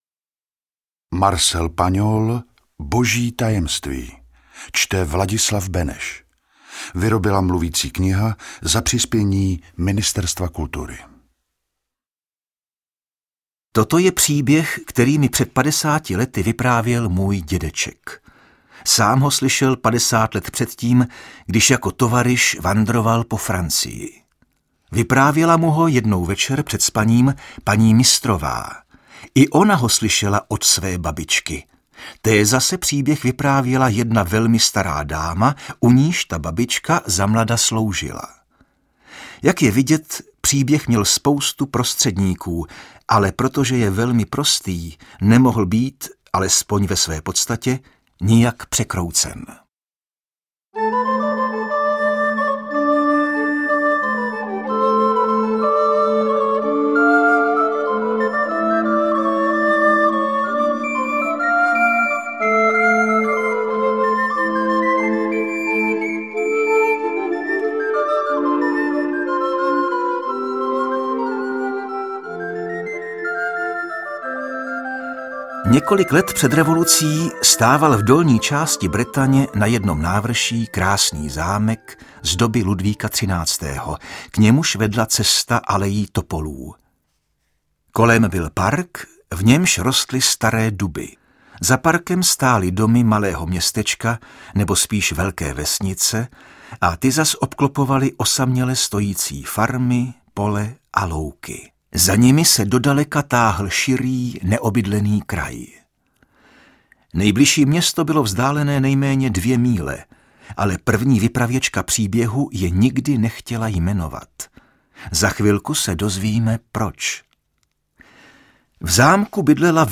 Čte: Vladislav Beneš